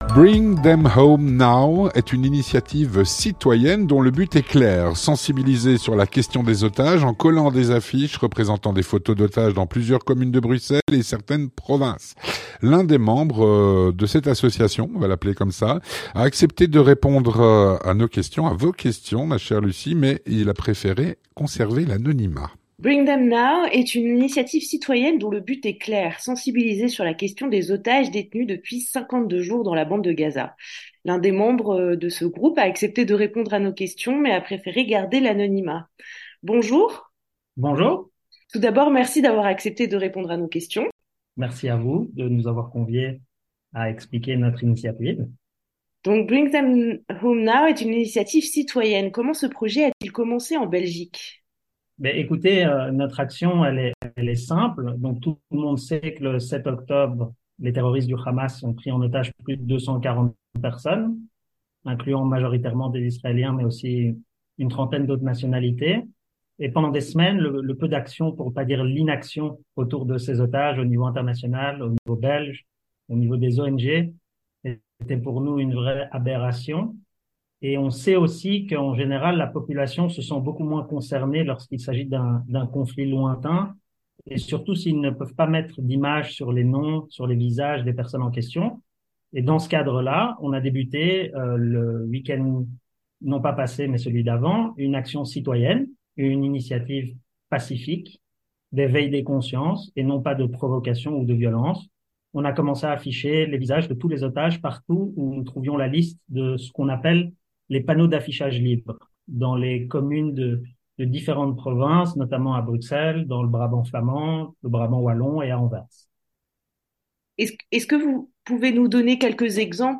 Avec l’un des membres de ce groupe qui a accepté de répondre à nos questions, mais qui a préféré garder l’anonymat.